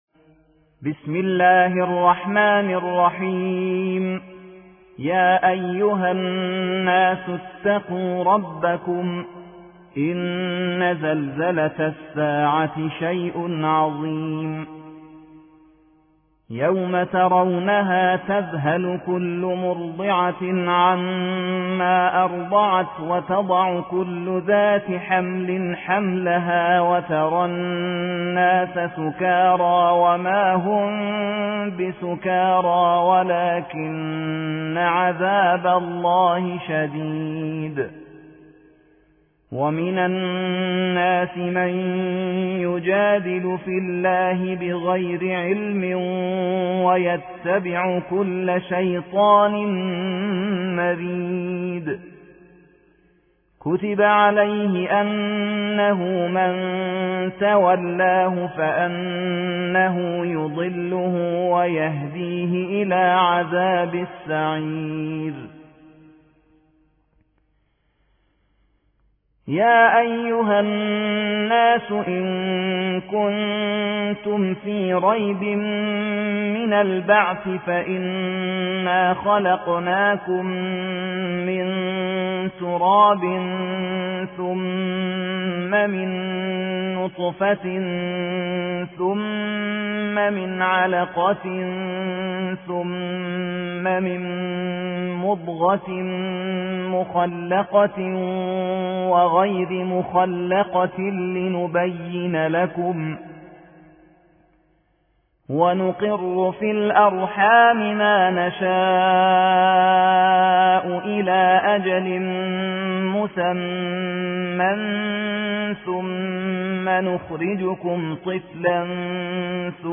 22. سورة الحج / القارئ